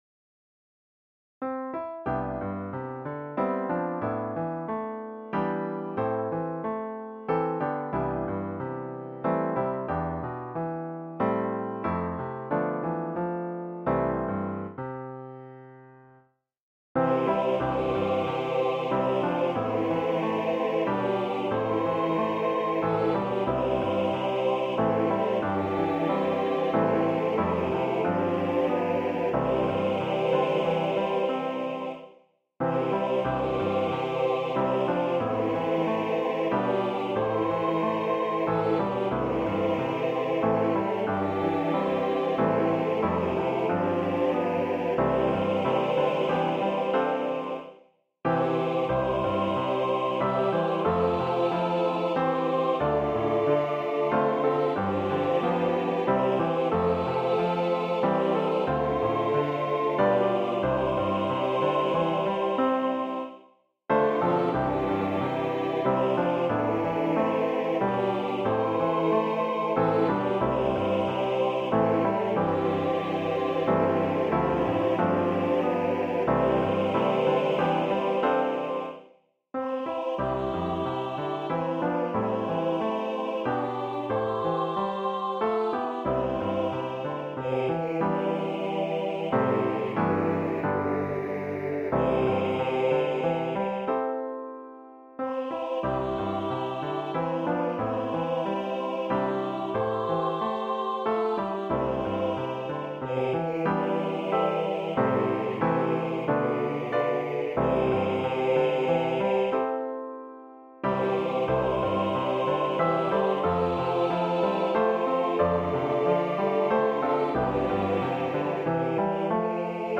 SATB, Youth Choir Mixed Or Unison